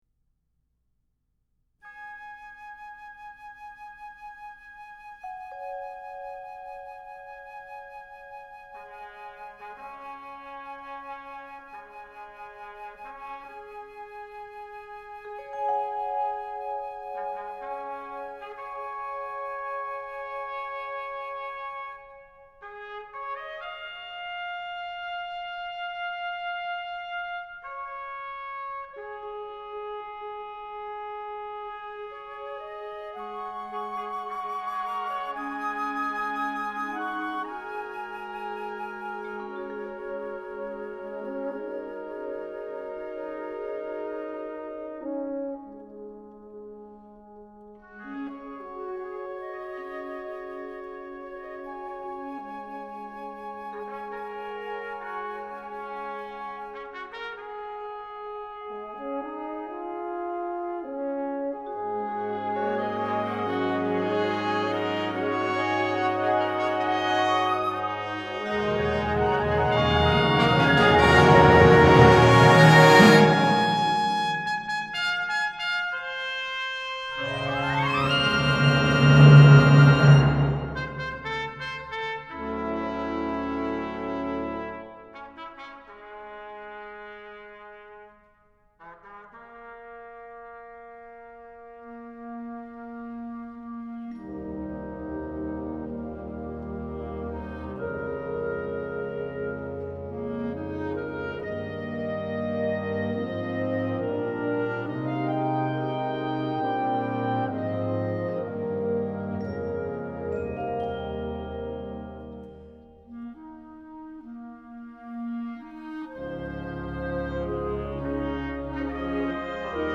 Genre: Band
Trumpet in Bb 1 (w/ Solo Cornet)